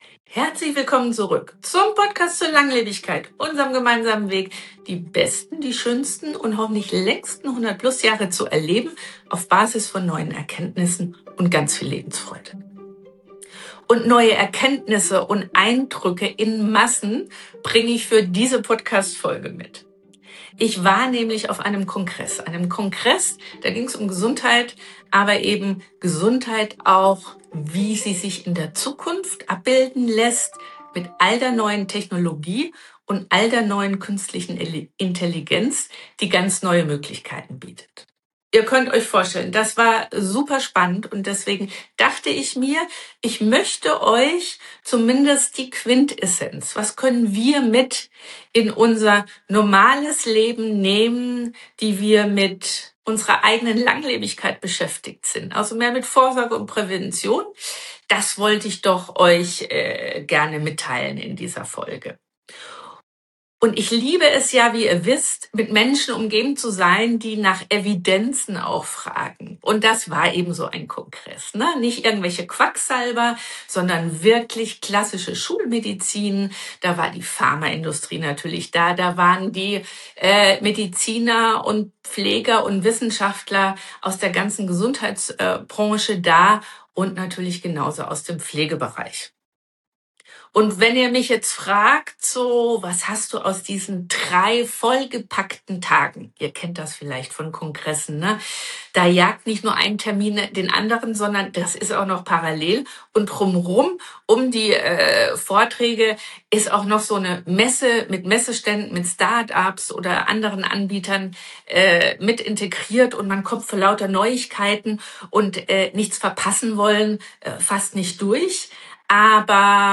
In dieser Solo-Folge teile ich Eindrücke vom Health Tech Summit in Basel – einem internationalen Kongress, auf dem Mediziner:innen, Wissenschaftler:innen, Tech-Unternehmen und Pflegeexpert:innen die Zukunft der Gesundheit aufzeigen.